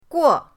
guo4.mp3